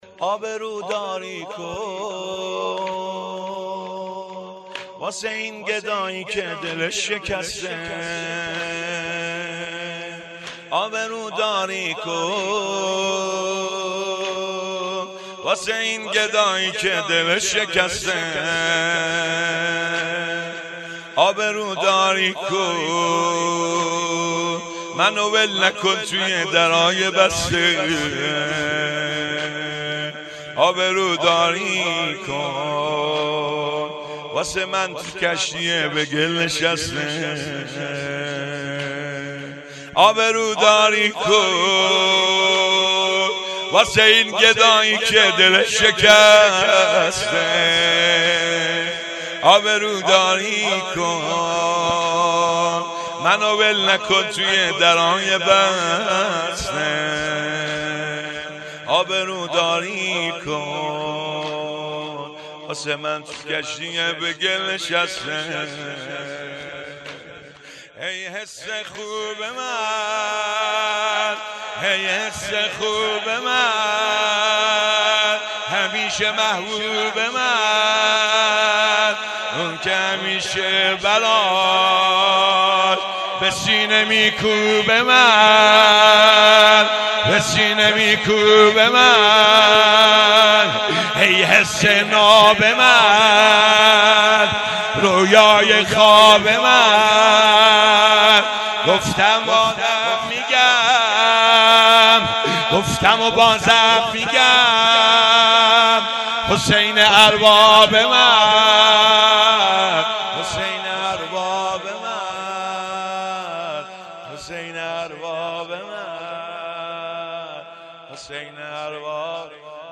شور پایانی
شهادت حضرت محسن ابن علی علیه السلام ۱۴۰۲